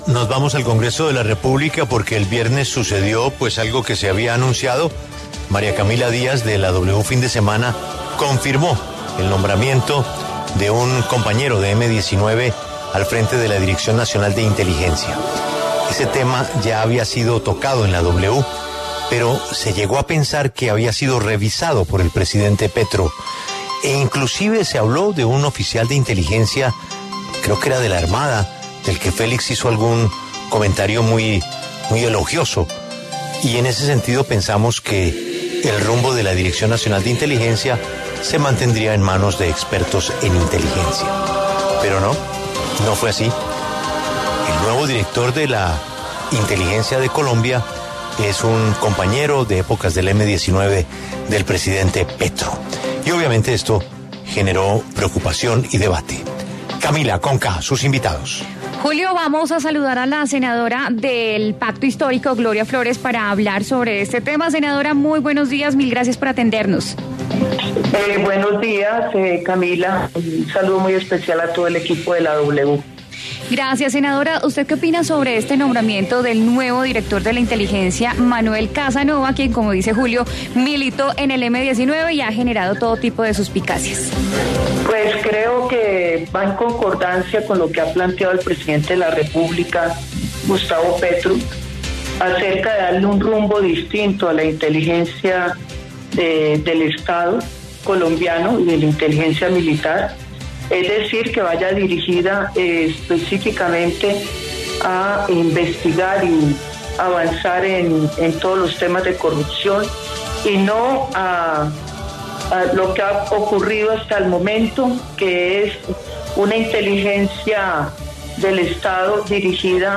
La senadora del Pacto Histórico Gloria Flórez y José Vicente Carreño del Centro Democrático, hablaron en La W sobre el nombramiento de Manuel Alberto Casanova como director nacional de inteligencia.